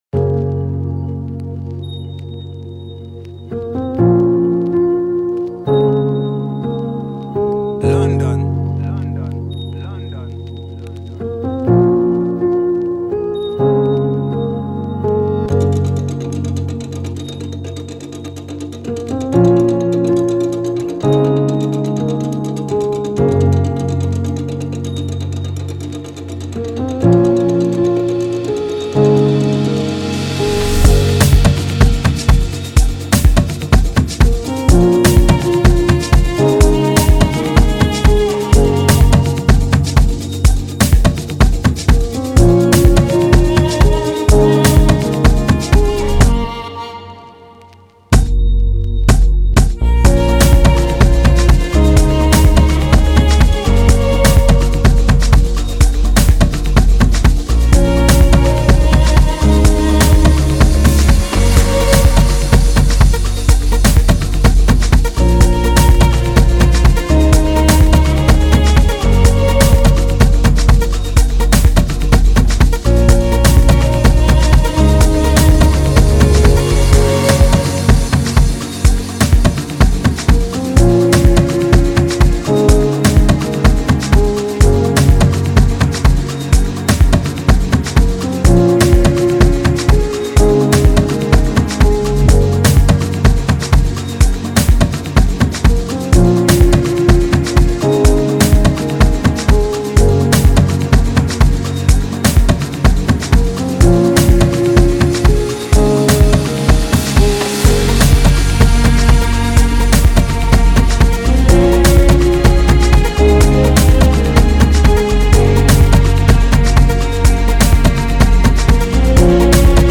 2025 in Dancehall/Afrobeats Instrumentals